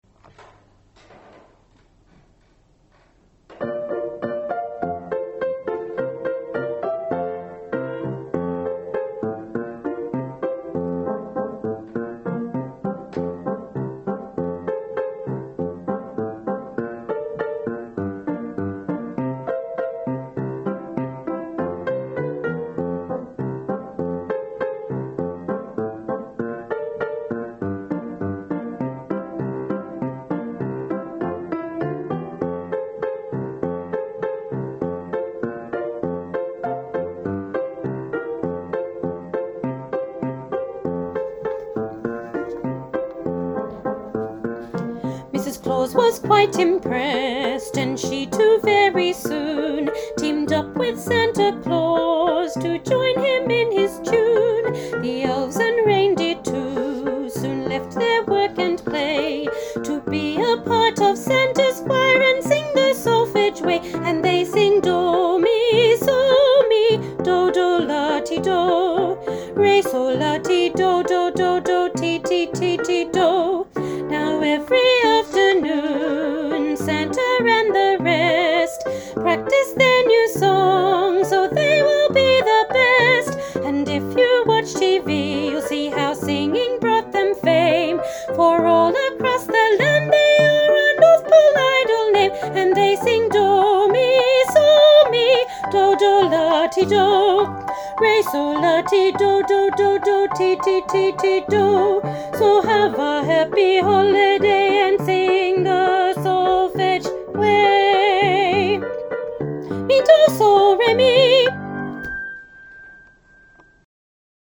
Elementary Choir – Solfege Santa – Part 2